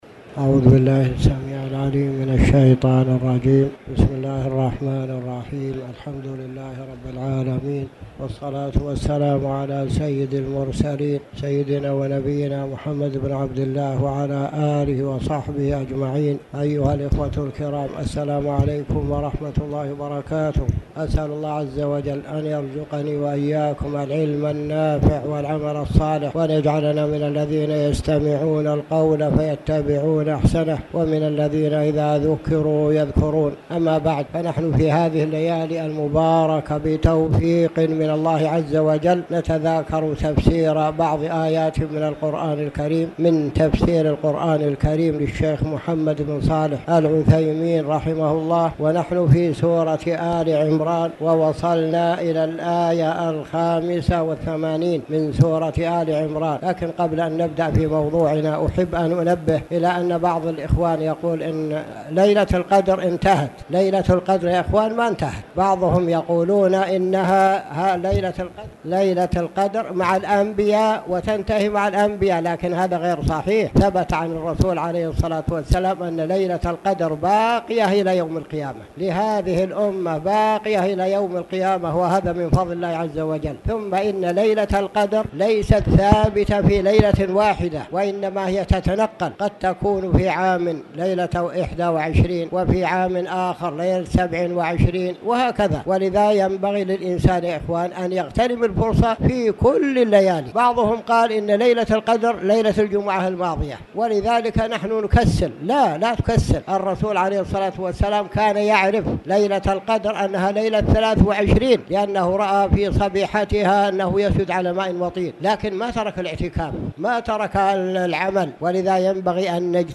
تاريخ النشر ٢٤ رمضان ١٤٣٨ هـ المكان: المسجد الحرام الشيخ